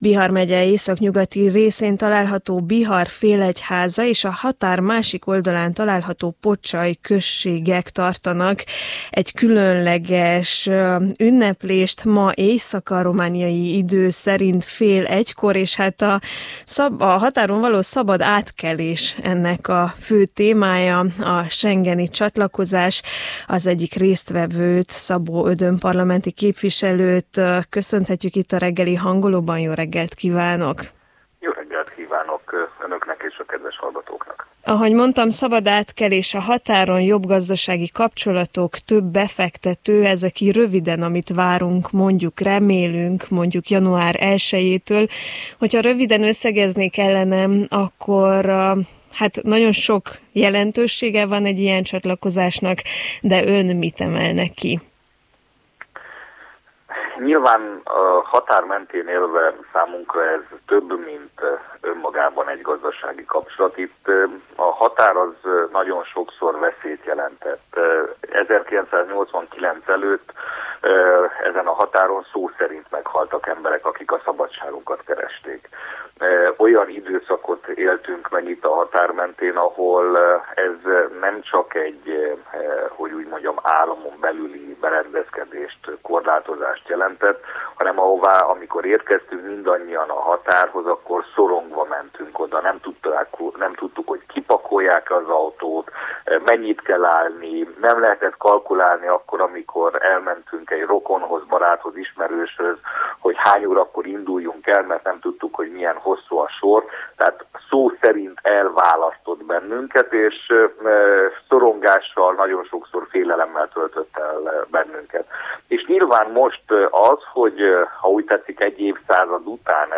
Közösen ünnepli meg Biharfélegyháza és Pocsaj a schengeni csatlakozást. Szabó Ödön parlamenti képviselő mesélt a részletekről.
A csatlakozásnak gazdasági haszna is lesz, de mitől különleges ez a határ mentén élőknek? Szabó Ödön volt a Hangoló vendége.